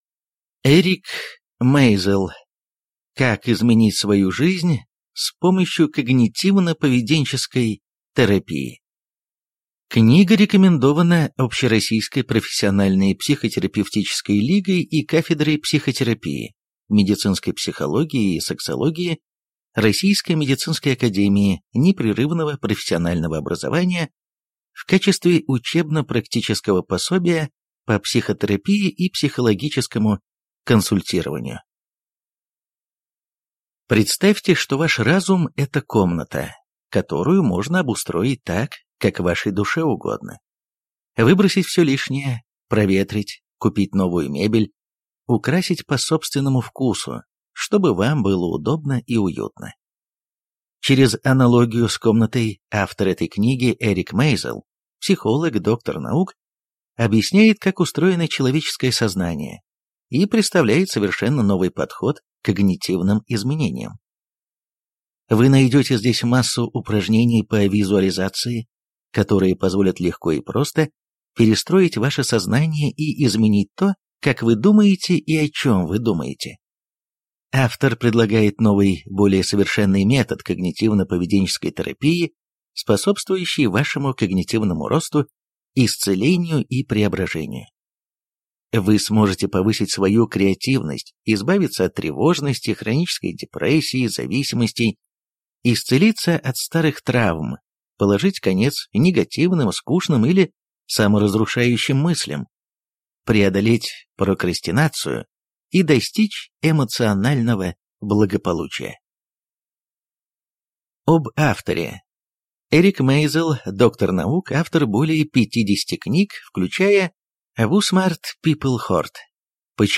Аудиокнига Как изменить свою жизнь с помощью когнитивно-поведенческой терапии | Библиотека аудиокниг